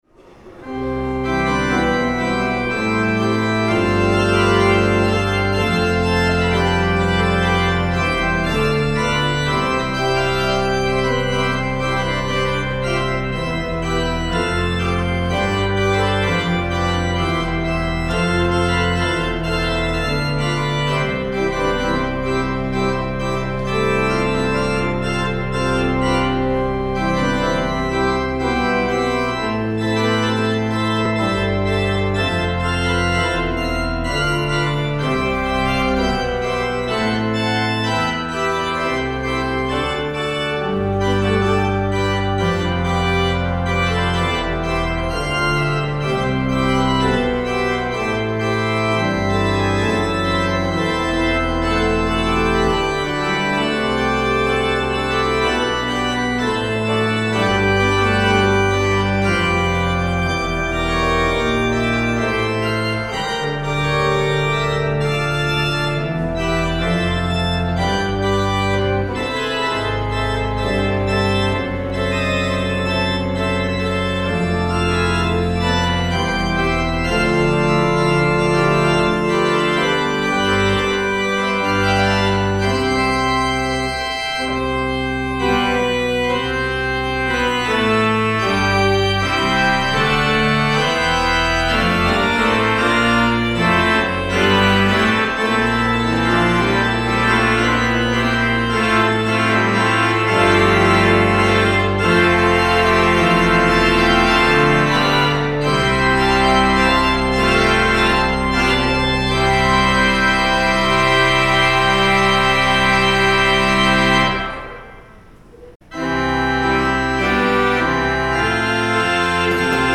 John 20:1-18 Sermon
Anthem
The Lord’s Prayer (sung)
Postlude